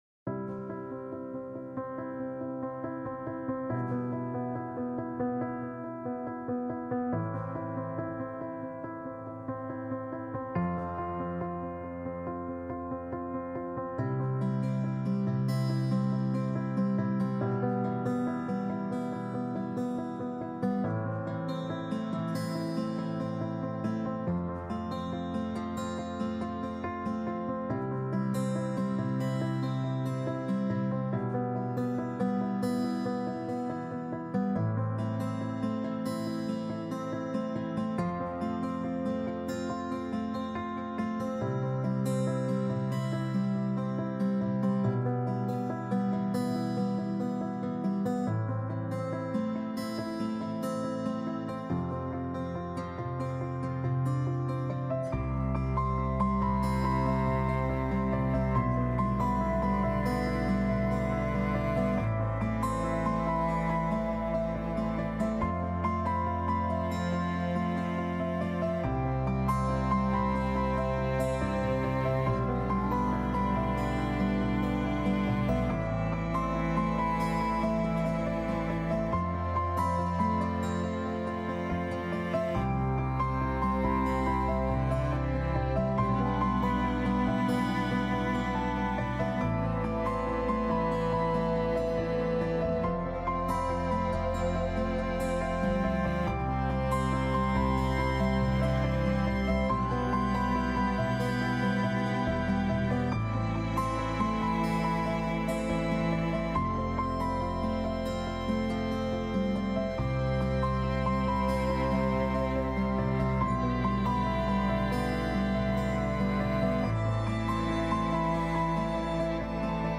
Emotional-background-piano_Low.mp3